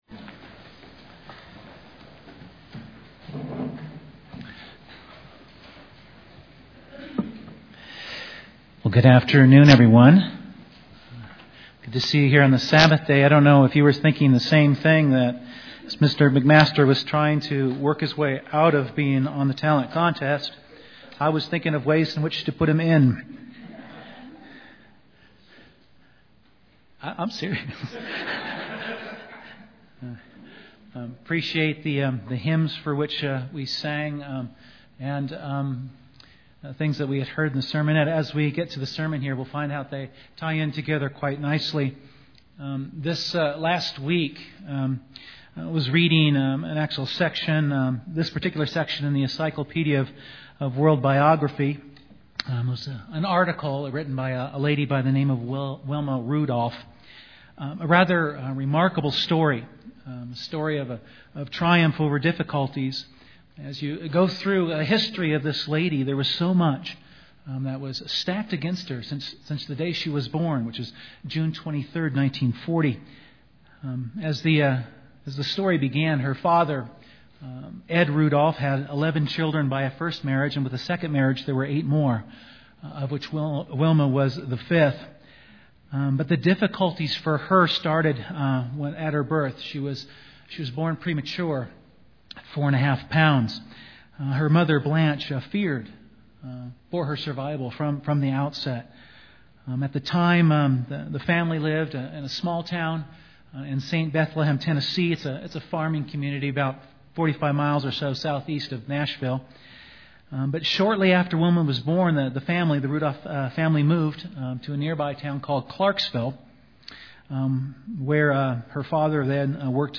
Given in Colorado Springs, CO
UCG Sermon Studying the bible?